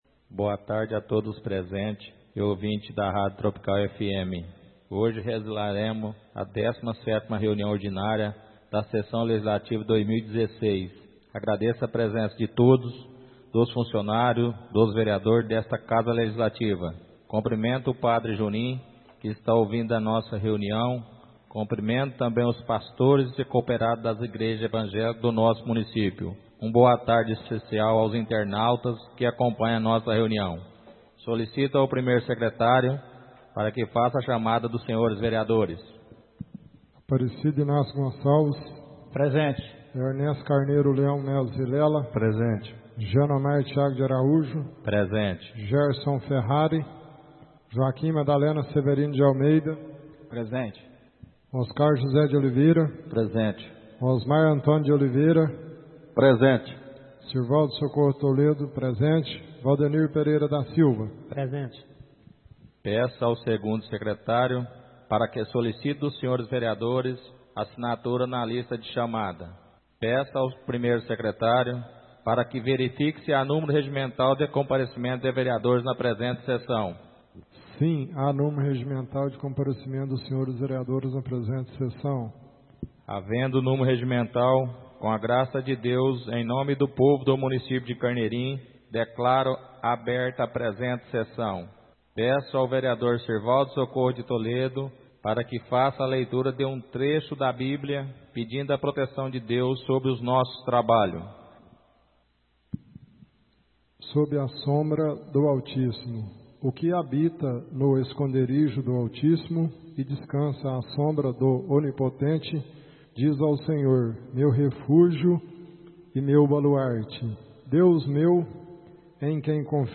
Áudio da 17ª reunião ordinária de 2016, realizada no dia 07 de Novembro de 2016, na sala de sessões da Câmara Municipal de Carneirinho, Estado de Minas Gerais.